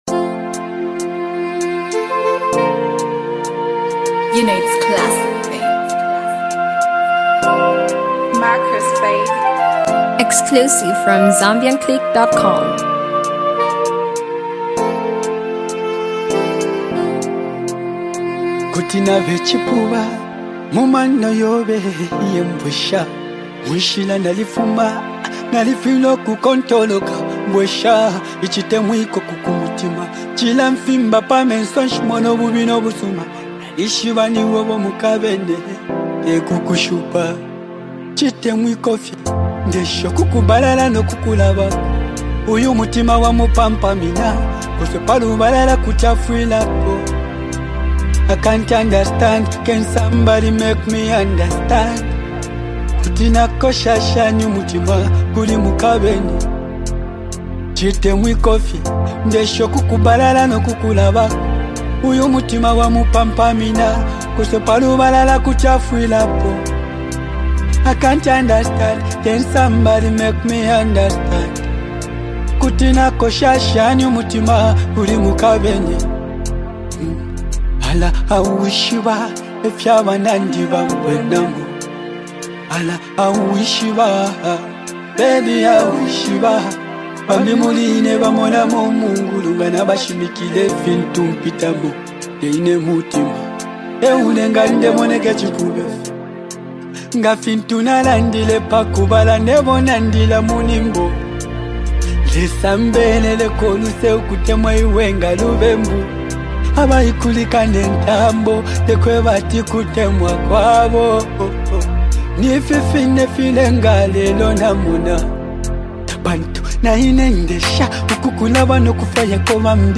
love-catching melody song
country groove